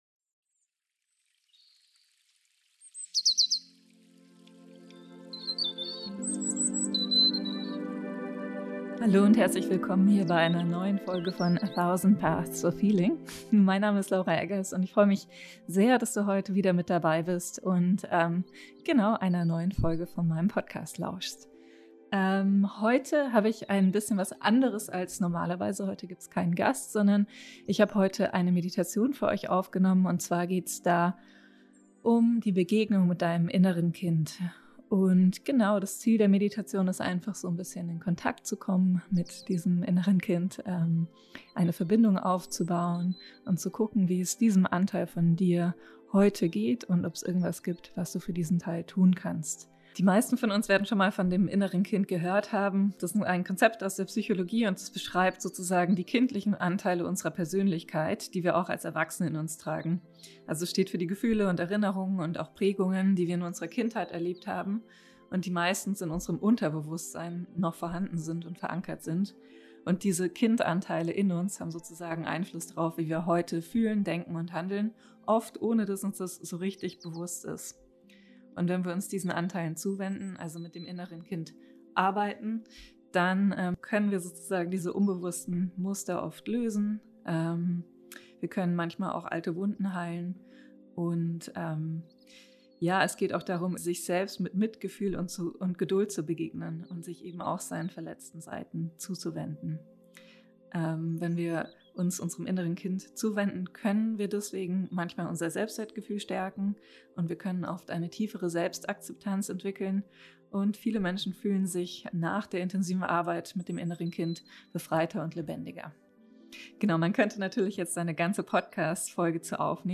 Meditation: Begegnung mit dem inneren Kind